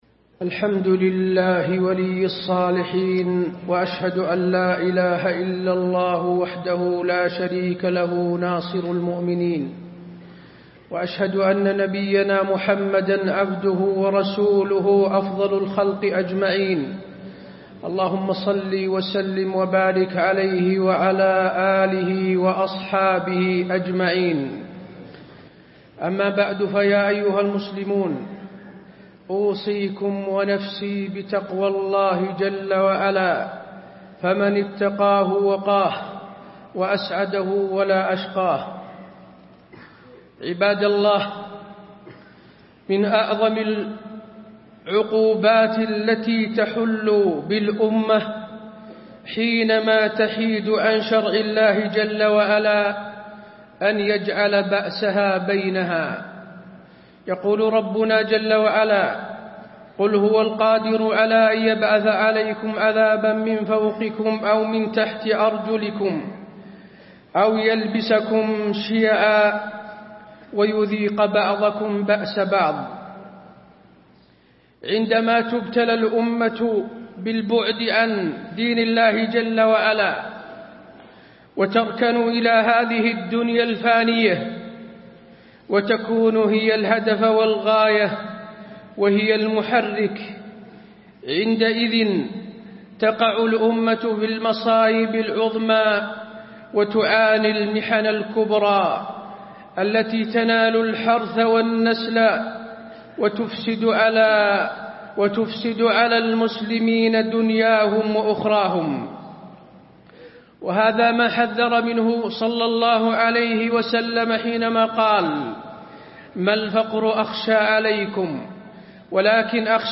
تاريخ النشر ١٦ ربيع الأول ١٤٣٥ هـ المكان: المسجد النبوي الشيخ: فضيلة الشيخ د. حسين بن عبدالعزيز آل الشيخ فضيلة الشيخ د. حسين بن عبدالعزيز آل الشيخ الفرقة بين المسلمين